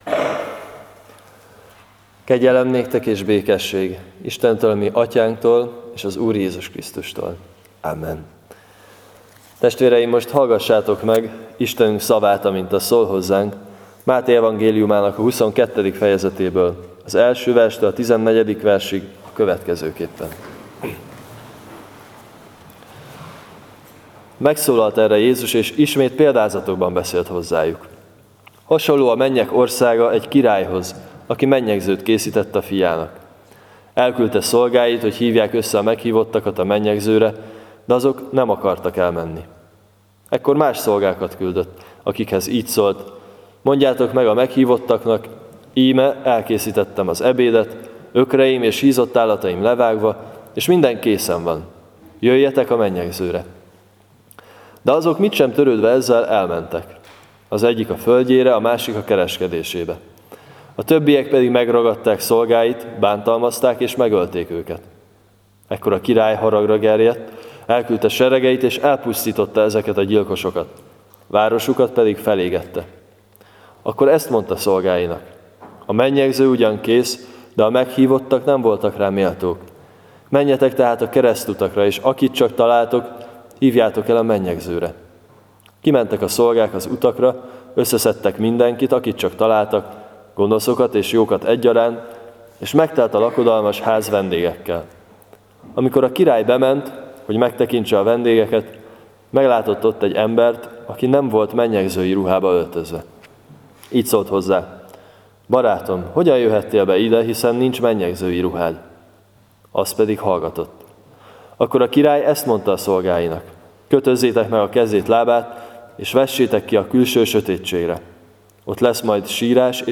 10.09. Mt 22-1-14 igehirdetes.mp3 — Nagycserkeszi Evangélikus Egyházközség